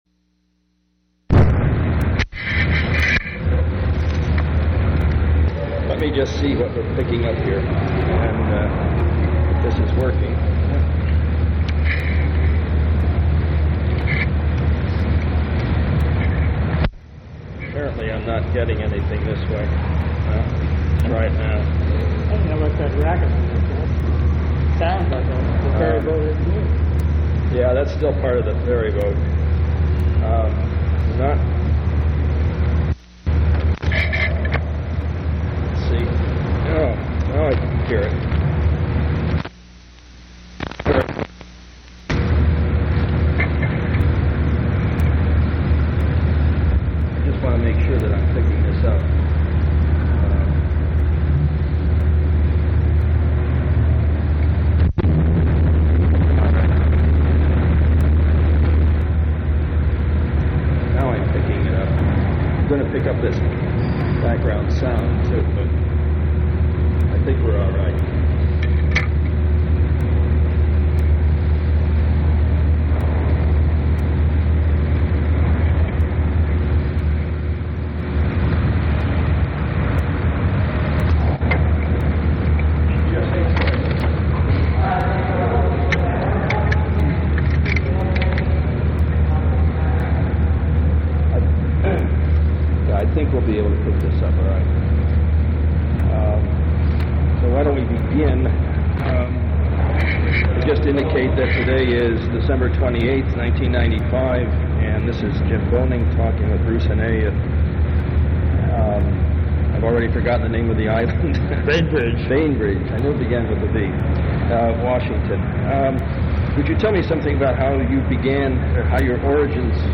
Oral histories
Place of interview Washington (State)--Seattle